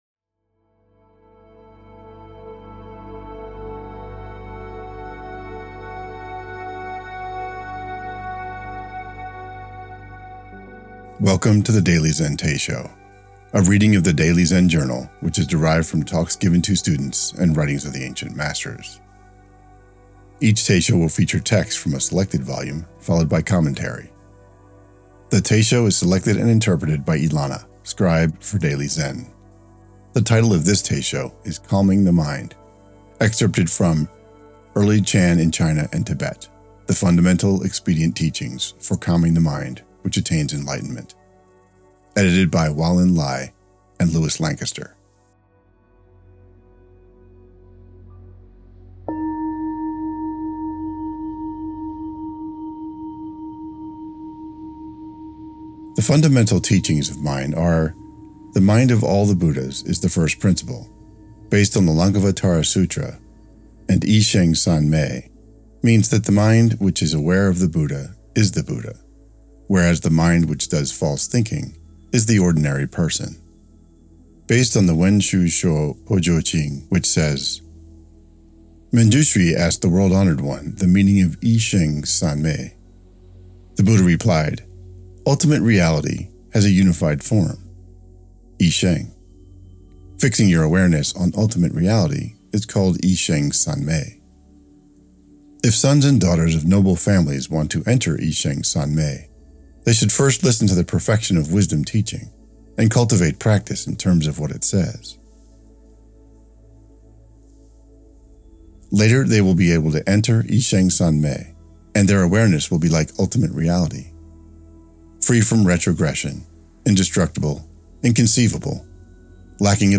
The Daily Zen Teisho Calming the Mind Play Episode Pause Episode Mute/Unmute Episode Rewind 10 Seconds 1x Fast Forward 30 seconds 00:00 / 9:44 Download file | Play in new window | Duration: 9:44